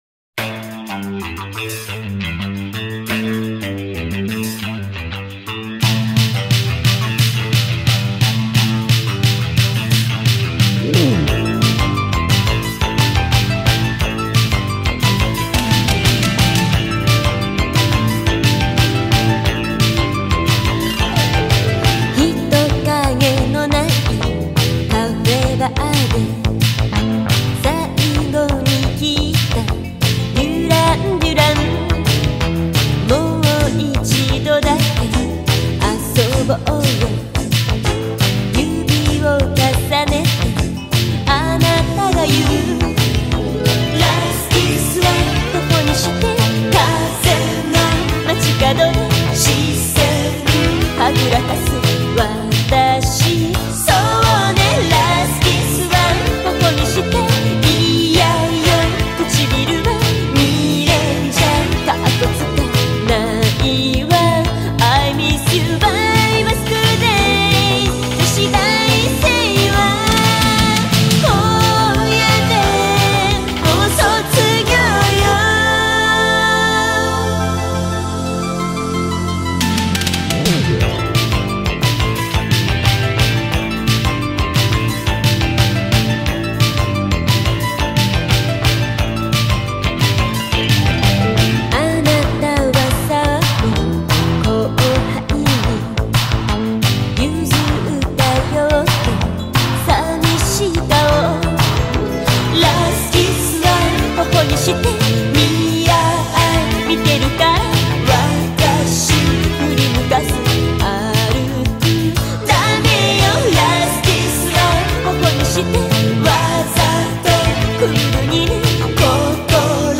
Собрал несколько подборок японской музыки
подборка № 1 (Radio Edit)